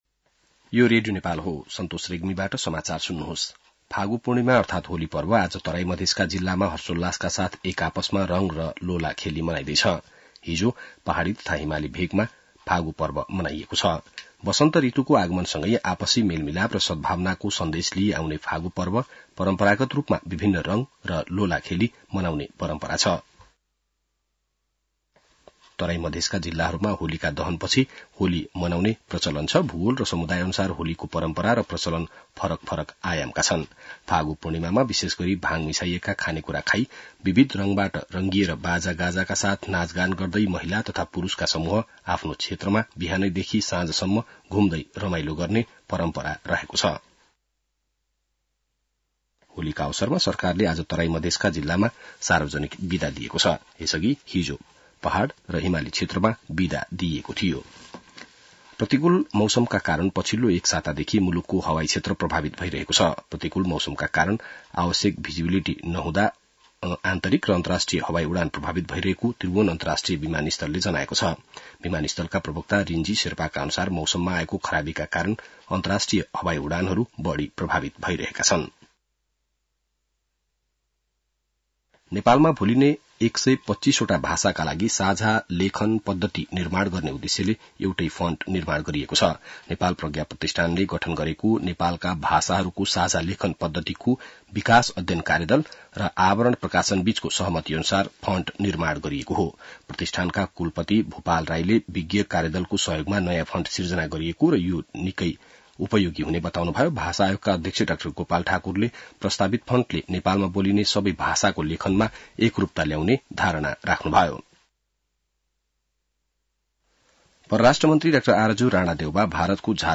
An online outlet of Nepal's national radio broadcaster
बिहान ६ बजेको नेपाली समाचार : १ चैत , २०८१